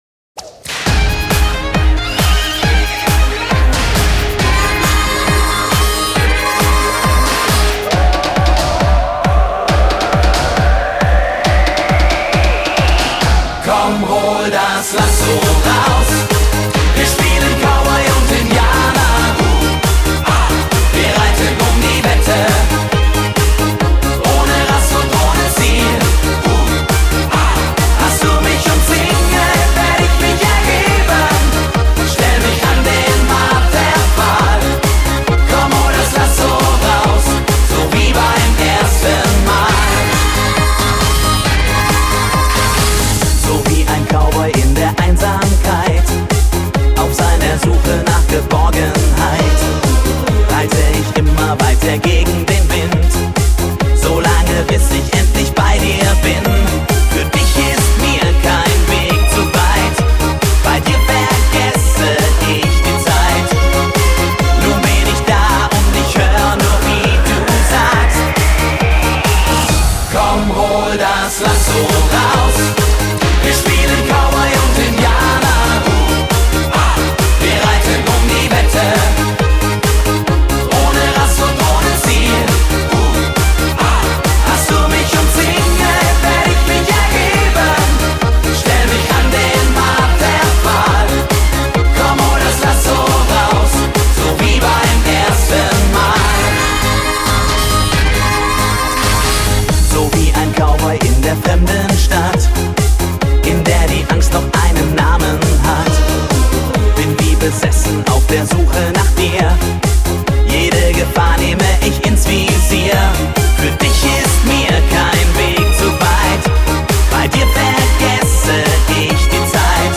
Немецкие танцевальные хиты